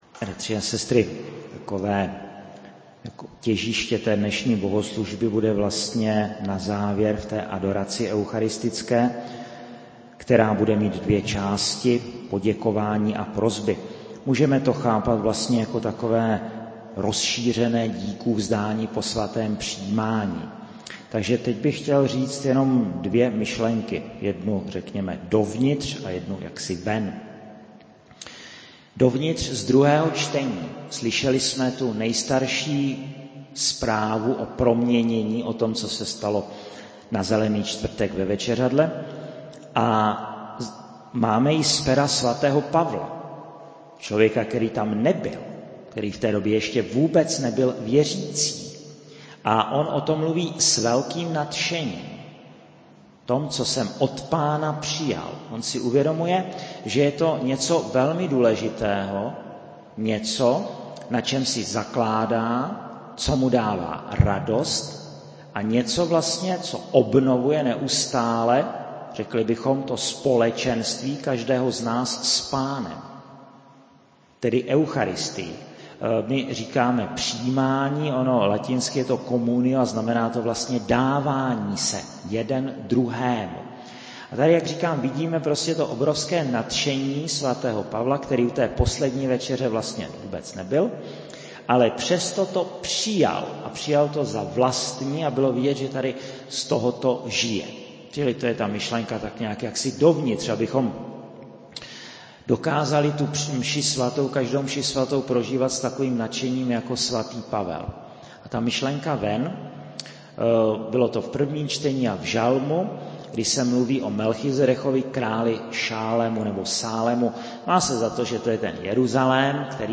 20.06.2019 - čtvrtek, kostel sv. Jakuba ve Veverské Bítýšce
[MP3, mono, 16 kHz, VBR 21 kb/s, 0.77 MB]
homilie1001.mp3